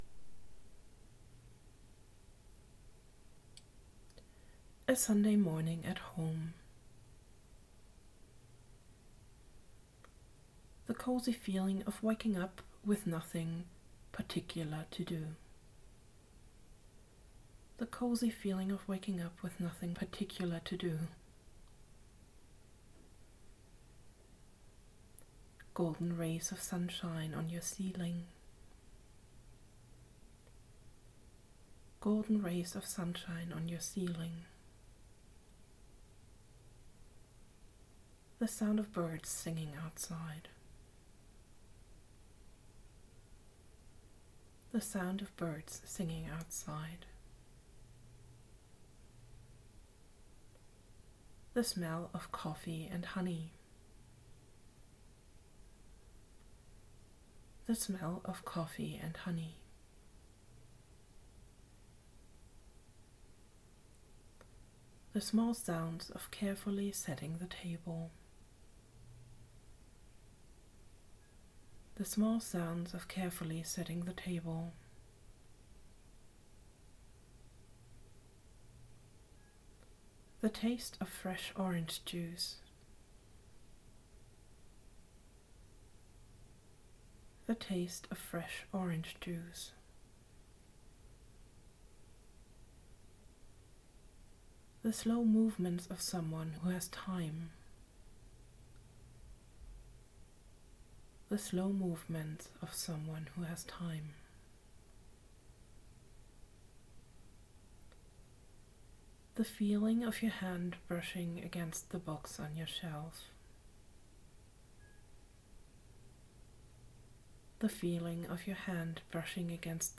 by on in english, guided imagery
In this short-form approach you get instructions to focus on imagining one sensory experience after the other. The instruction is repeated twice and then you are asked to shift to the next picture or sensory experience immediately. It is supposed to be so fast-paced that you have no time to come up with negative ideas or elaborate inner stories.